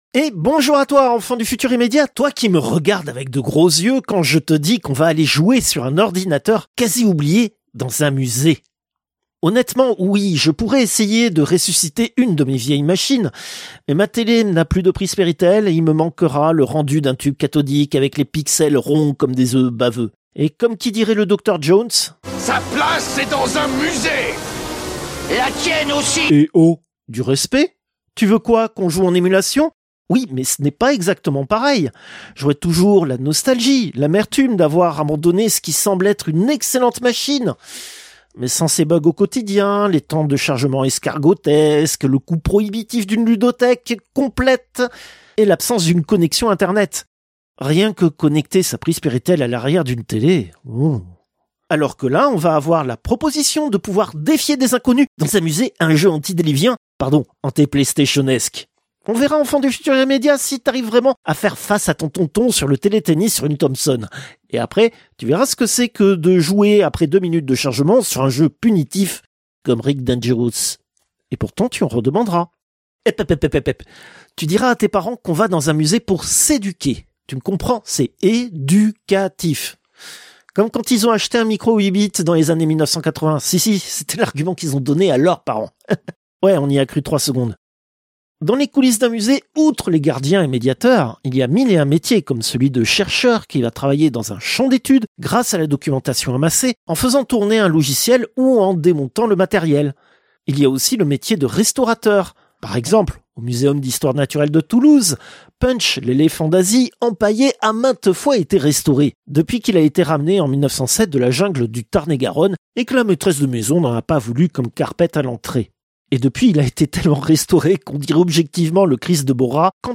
Chroniques › Enfant du futur immédiat
Extrait de l'émission CPU release Ex0229 : Musée informatique, première partie.